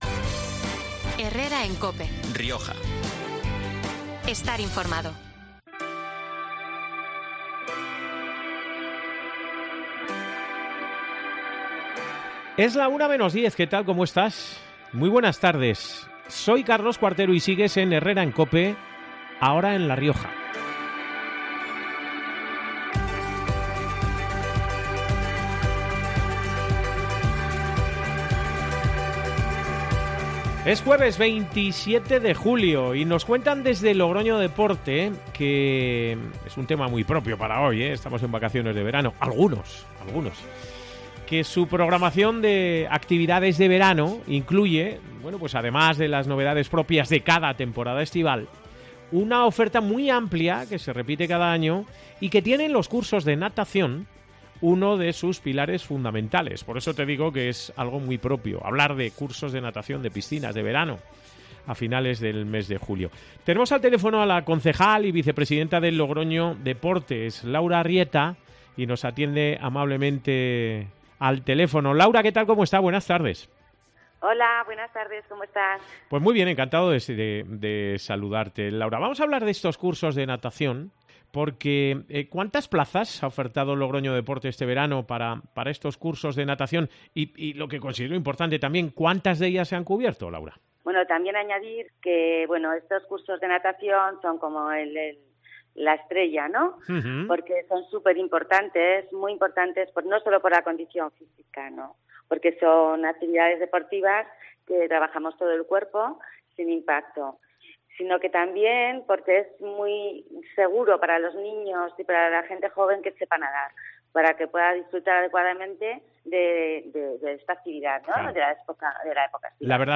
Laura Rivas, concejal y vicepresidenta de Logroño Deporte, ha explicado en COPE Rioja que "nuestra programación de actividades de verano incluye, además de las novedades propias de cada año, una oferta que se repite cada año y que, como la natación, componen el corazón del programa y forman parte de la esencia propia del verano".